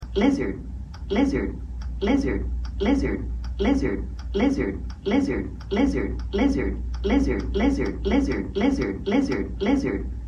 Lizard Sound Effects MP3 Download Free - Quick Sounds